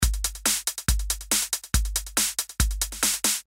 伦理恍惚的鼓声 Var 1
描述：Trance drums Var 1.电动电子恍惚节拍
Tag: 140 bpm Trance Loops Drum Loops 590.79 KB wav Key : Unknown